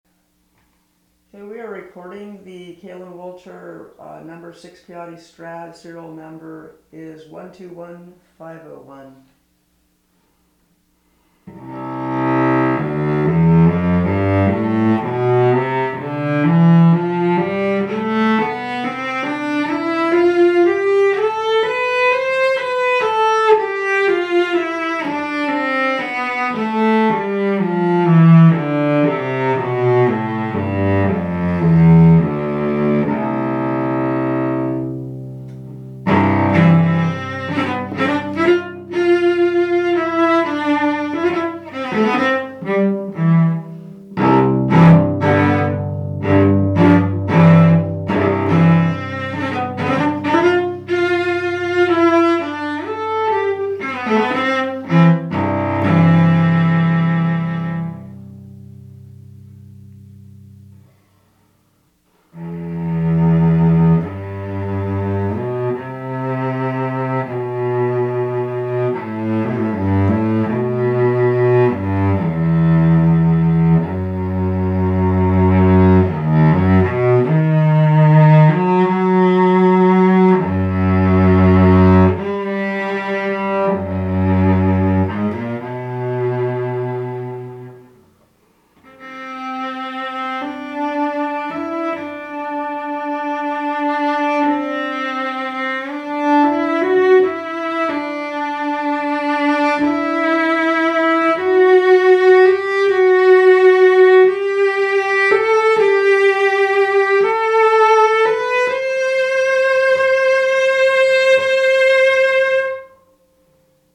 Bright, focused cello with reedy elegance